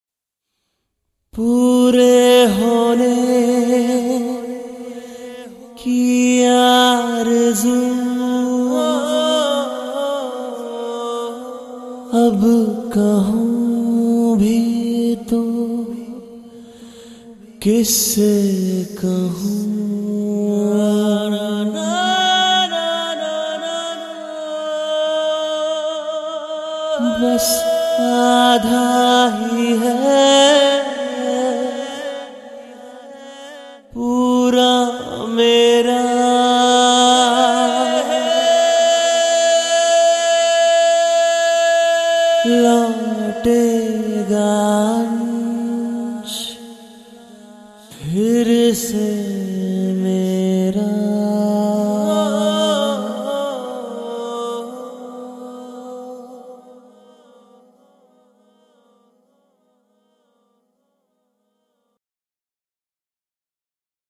Bollywood MP3 Songs 2012